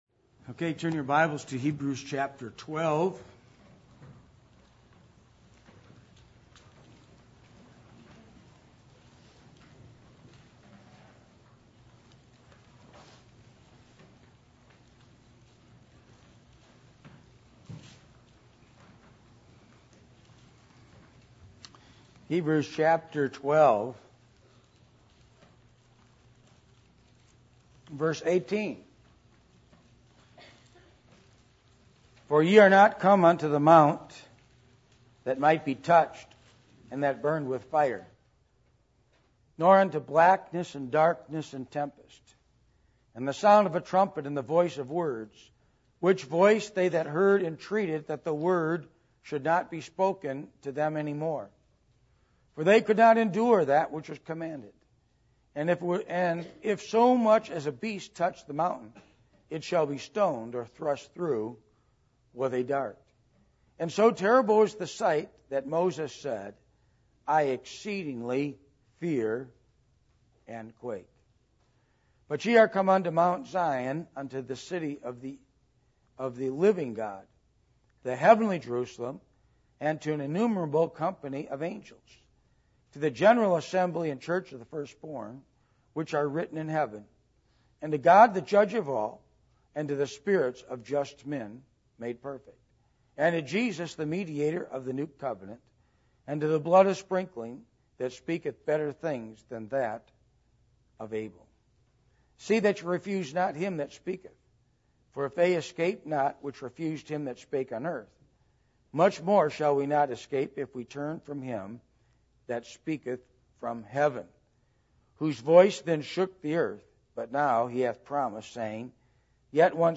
Passage: Hebrews 12:18-26 Service Type: Midweek Meeting %todo_render% « Making Right Decisions Biblical Principles Of Finances